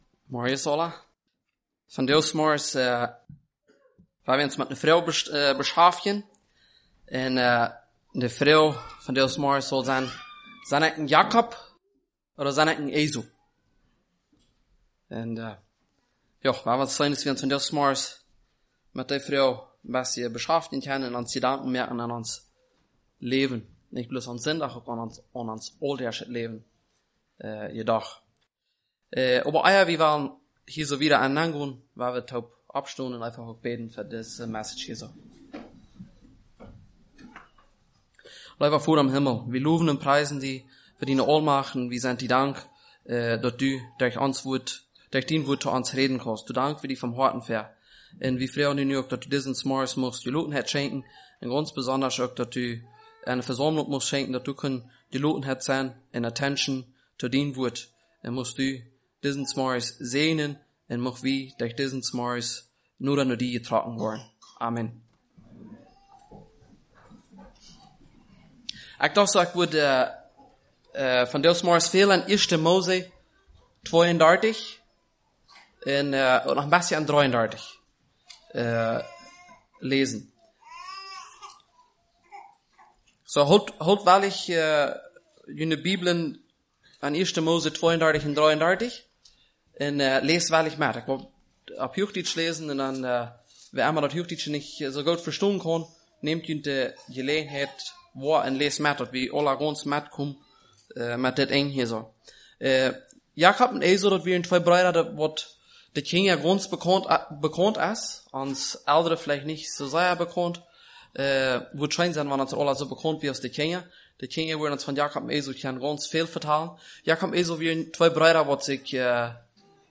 Sunday Message